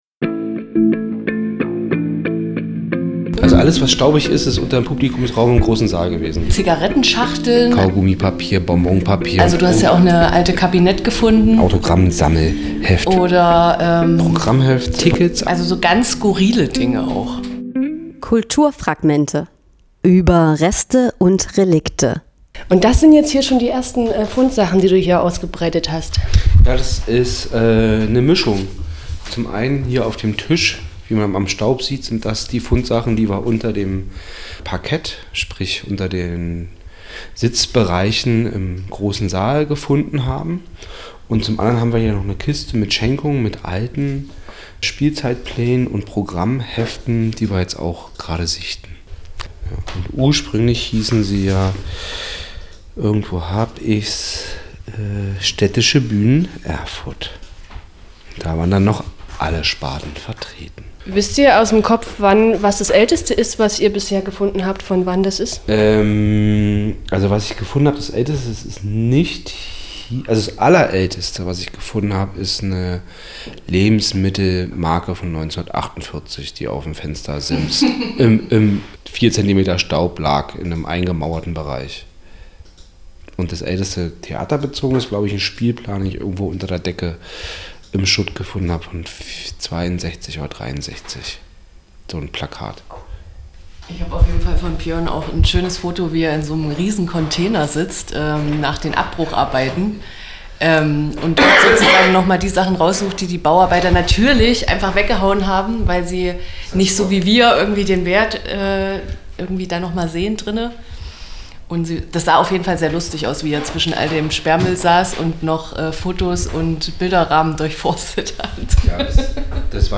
(Inkl. einführendem Vorgespräch